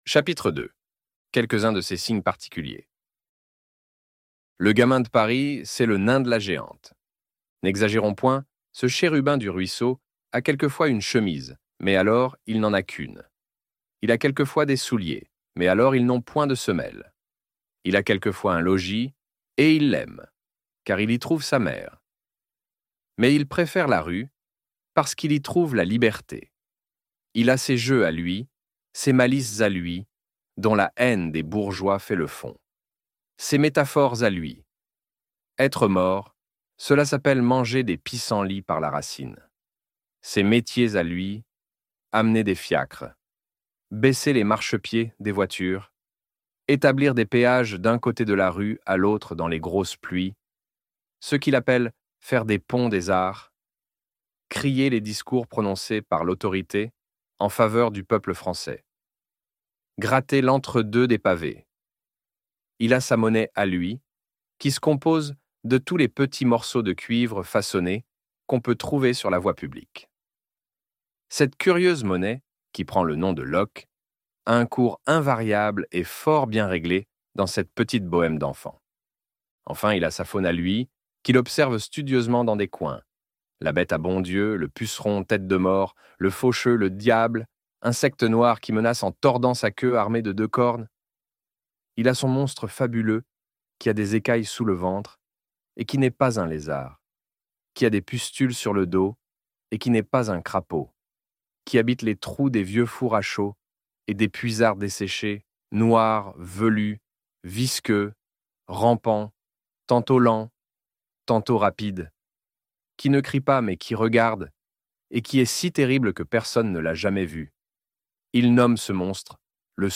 Les Misérables - Partie 2 - Livre Audio